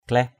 /klɛh/